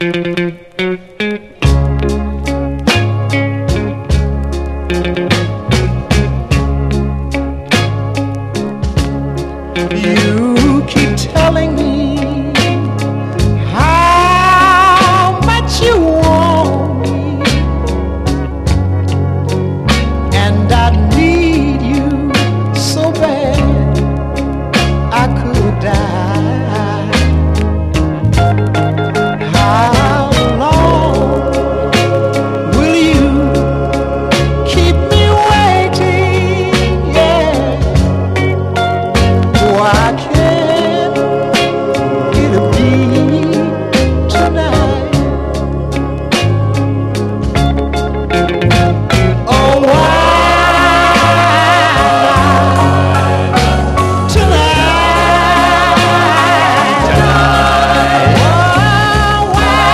RHYTHM & BLUES
ハッピー・スウィングR&B/ポップコーン！